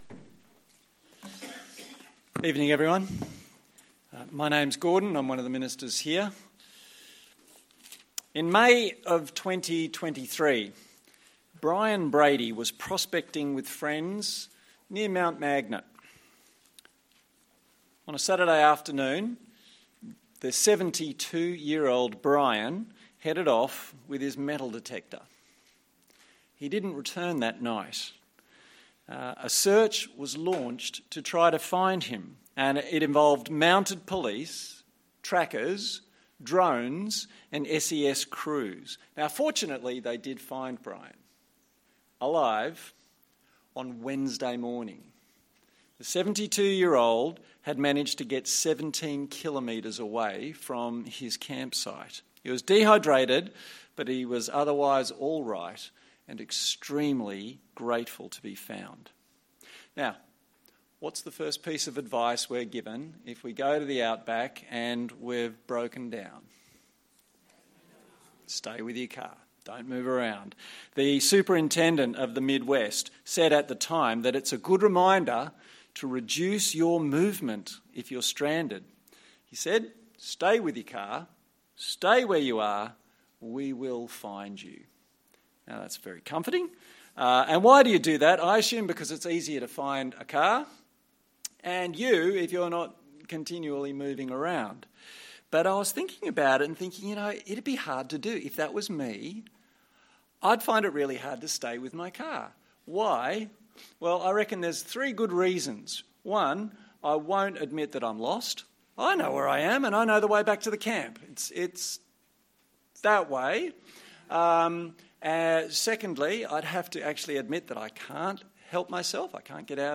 Luke Passage: Luke 15 Service Type: Sunday Evening Download Files Notes Topics